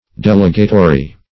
Search Result for " delegatory" : The Collaborative International Dictionary of English v.0.48: Delegatory \Del"e*ga*to*ry\, a. [L. delegatorius pert. to an assignment.] Holding a delegated position.